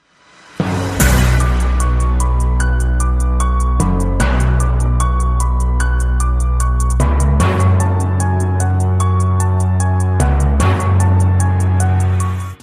Tải âm thanh "Dramatic tiktok" - Hiệu ứng âm thanh chỉnh sửa video